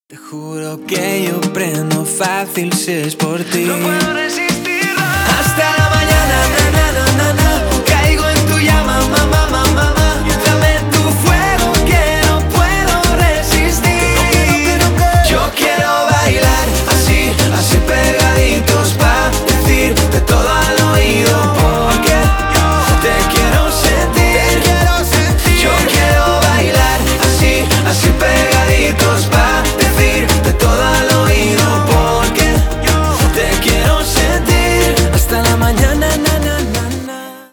Поп Музыка
клубные # латинские